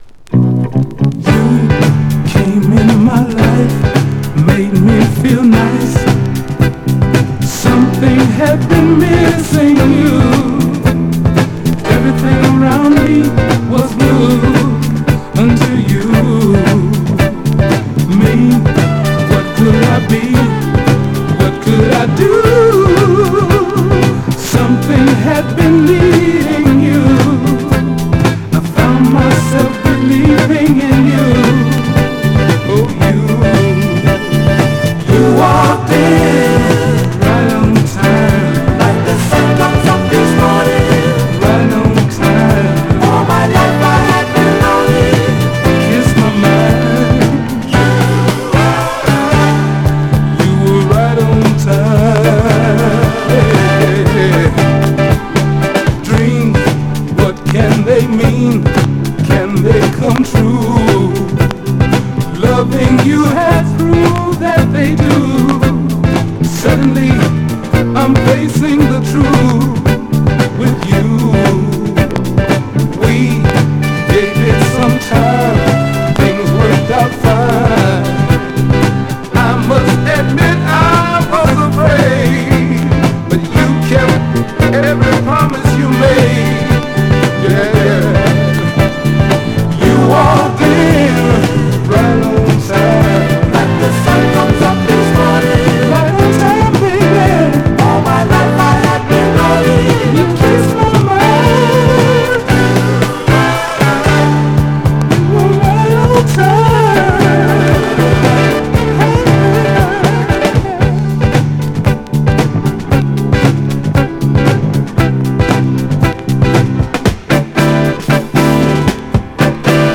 Soul/Funk　ファンクバンド
Side A中盤手前でキズによりノイズあり
試聴曲＝Side A記載ノイズ部分収録
※実物の試聴音源を再生状態の目安にお役立てください。